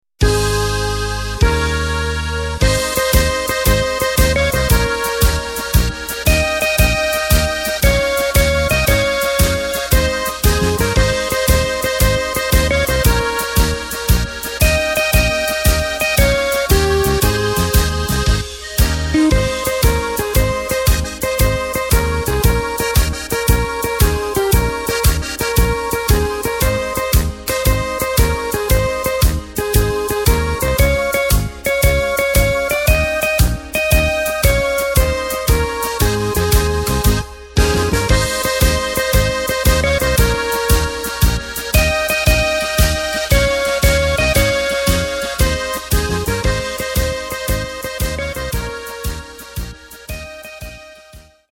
Takt:          2/4
Tempo:         100.00
Tonart:            Ab